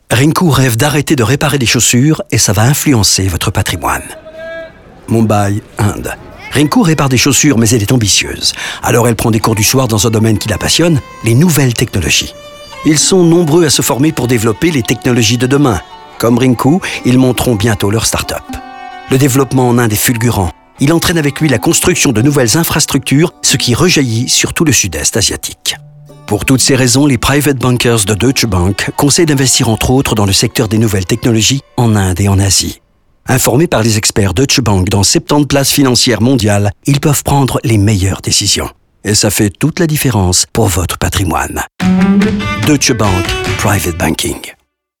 Nous prenons également le temps de développer les histoires de Rinku, Søren, Juan et Edward dans des spots radio de 45 secondes.